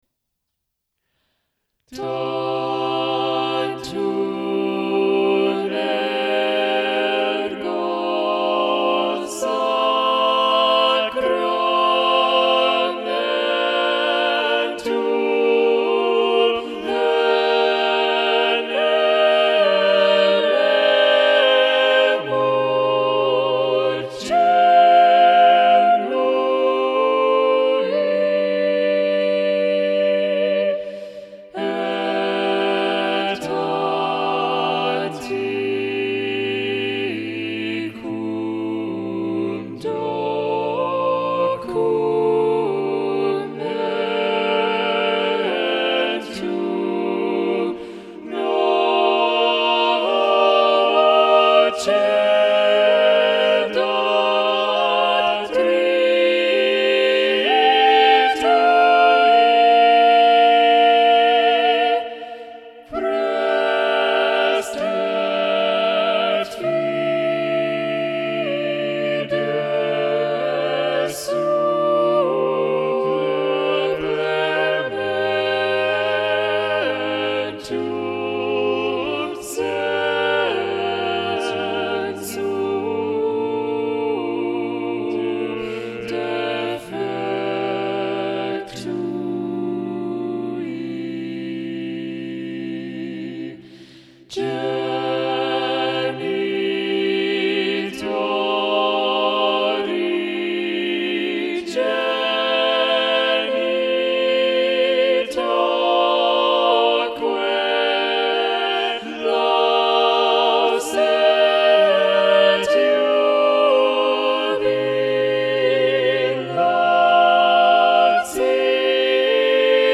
Thomas Aquinas Number of voices: 4vv Voicing: SATB Genre: Sacred, Motet
Language: Latin Instruments: A cappella